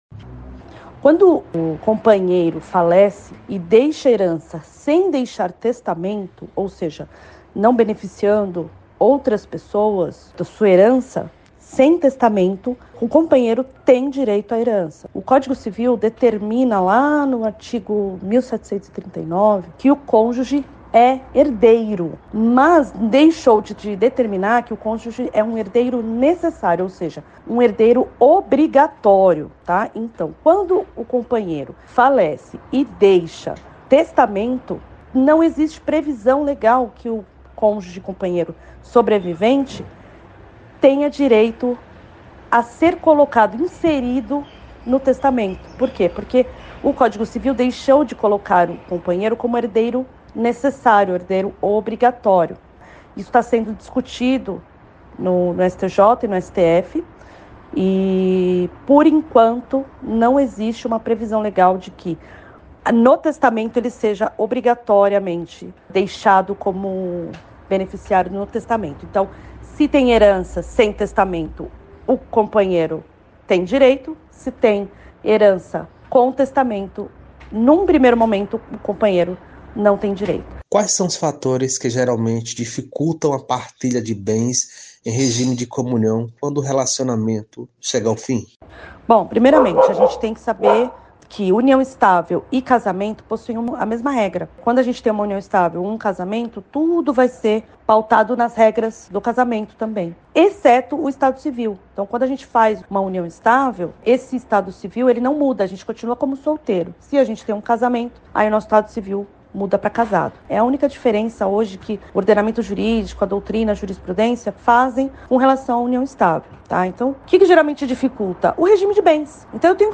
advogada especializada em Direito da Família explica sobre direito da herança